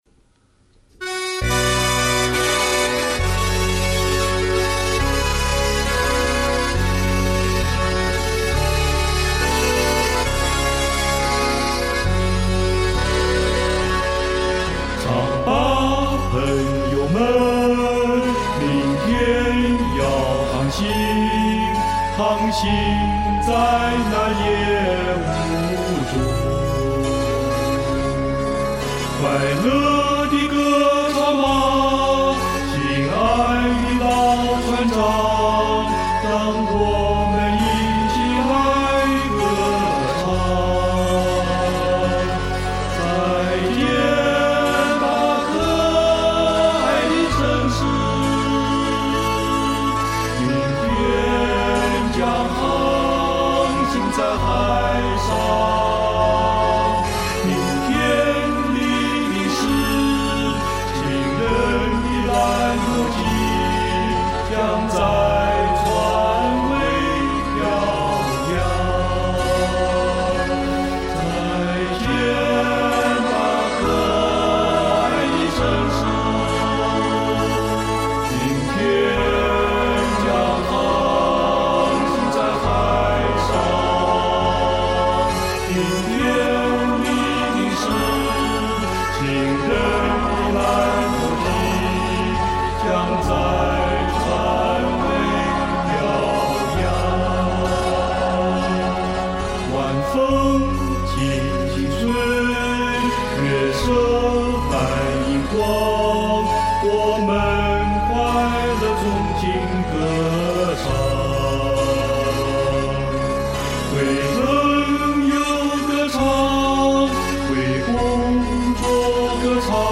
自男声三重唱